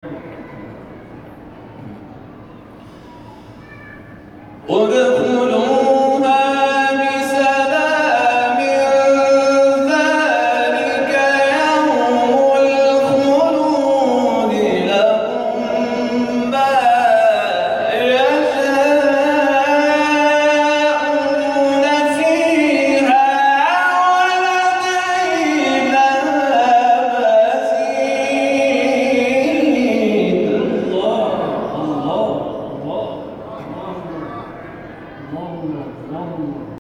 نغمات صوتی از قاریان ممتاز کشور
گروه شبکه اجتماعی: جدیدترین مقاطع صوتی از قاریان ممتاز کشوری را که در شبکه‌های اجتماعی انتشار یافته است، می‌شنوید.